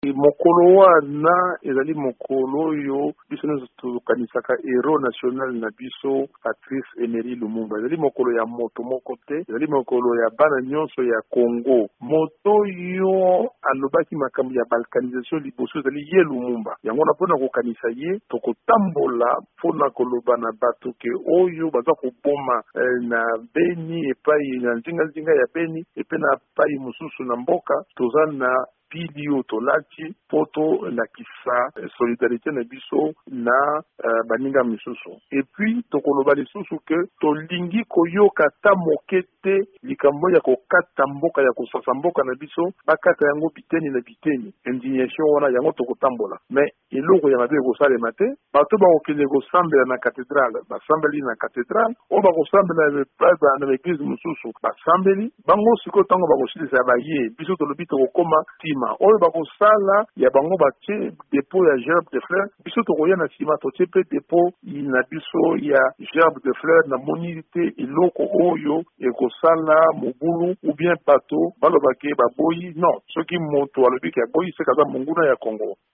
Martin Fayulu alobi esengeli kotambola le 17 janvier mpo ezali mokolo ya kopesa losako na Patrice Emery Lumumba moto ya yambo aboyaki balkanisation ya RDC. VOA Lingala ebengaki Martin Fayulu mpo na kolimbola malabo apesaki lobi loleki na bokutani na bapanzi sango.